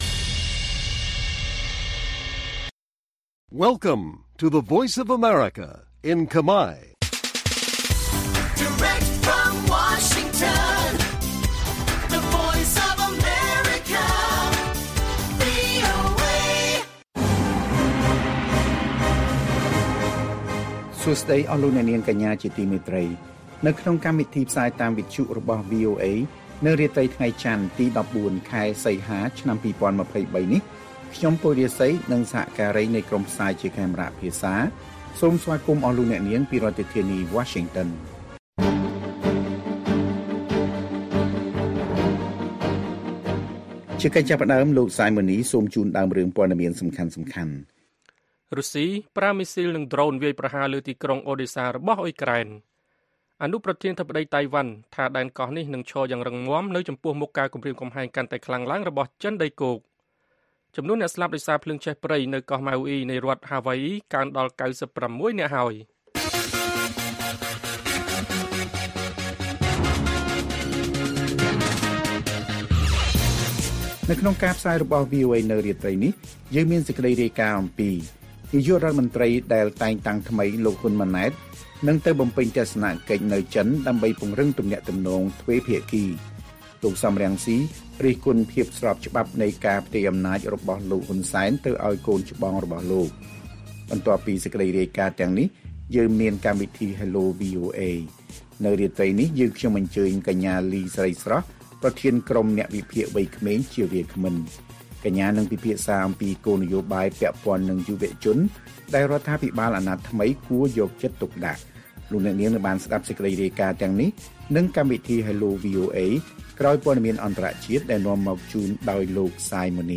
ព័ត៌មានពេលយប់ទី ១៤ សីហា៖ លោក ហ៊ុន ម៉ាណែត នឹងទៅបំពេញទស្សនកិច្ចនៅចិនដើម្បីពង្រឹងទំនាក់ទំនងទ្វេភាគី